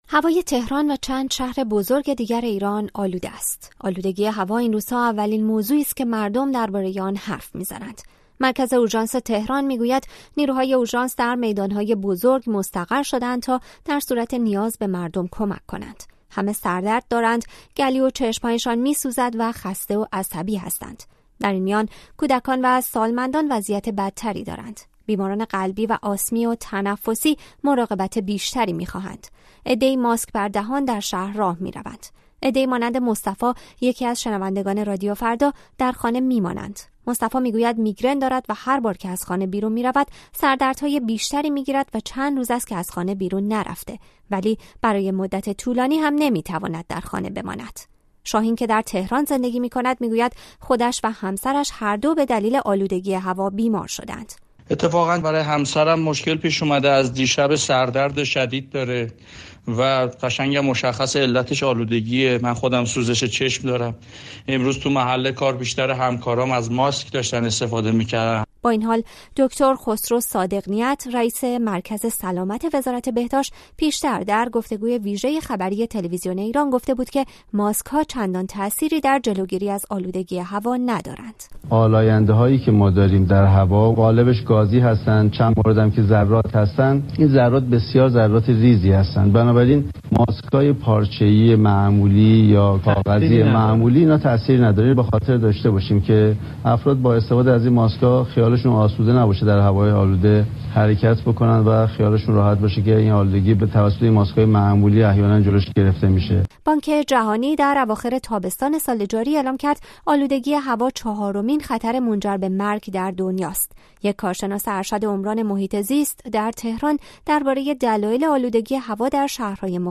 آلودگی هوا مدارس را تعطیل کرده اما باز هم هوا برای نفس کشیدن کم است. چند نفر از شهروندان تهرانی در گفت‌وگو با رادیو فردا از دلایل مختلف آلودگی هوا در تهران و چند شهر دیگر می‌گویند.